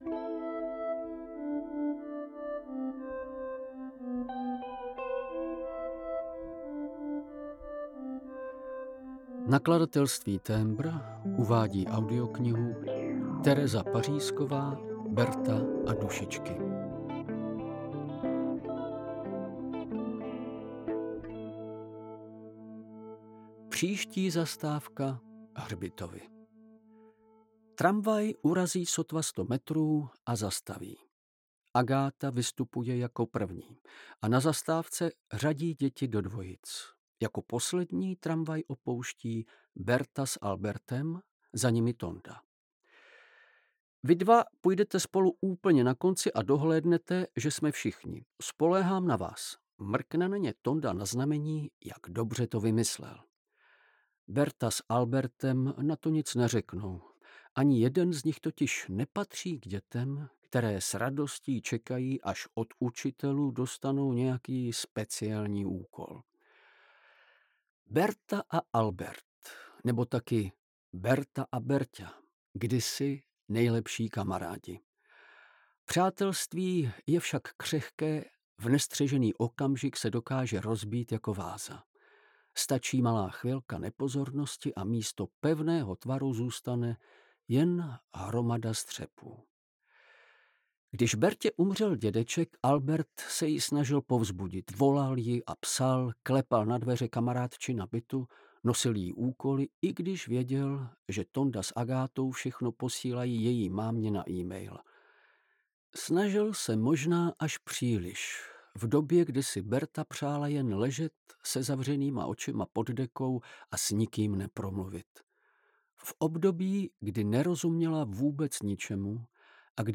Audiobook
Read: Vladimír Javorský